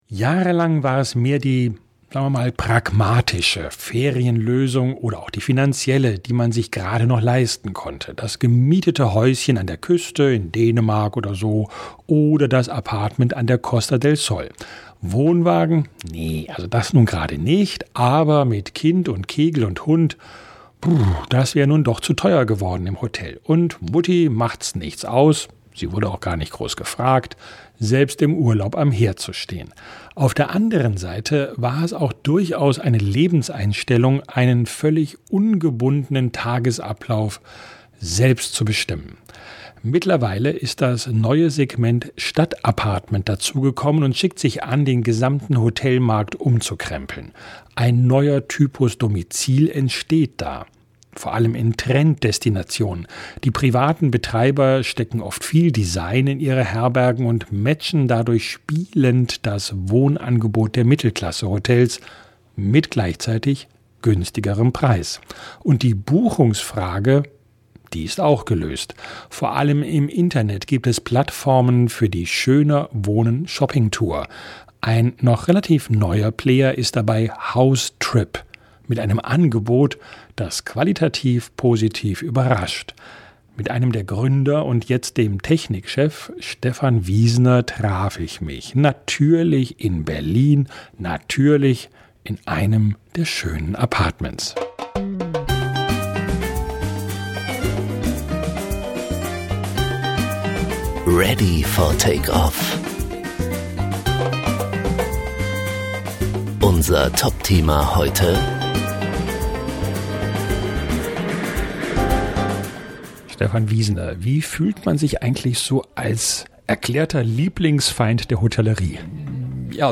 Direktlink: Gespräch